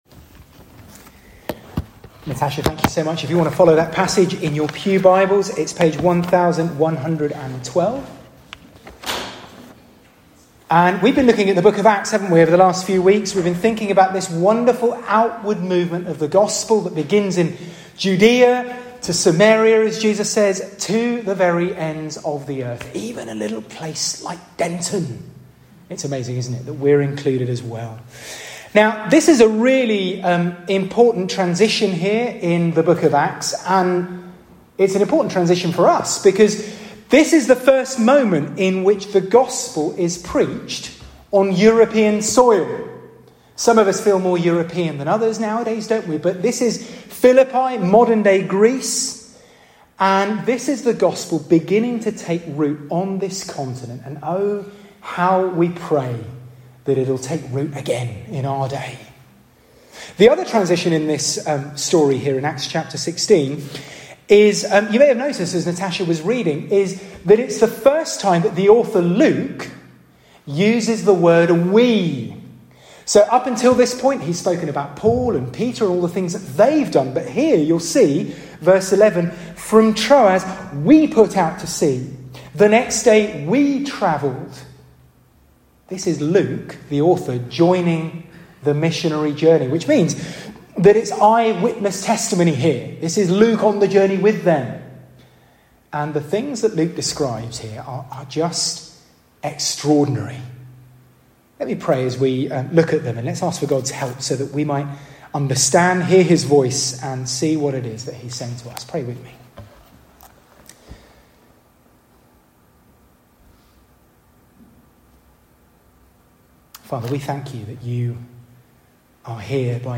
SERMON-23RD-FEBRUARY.mp3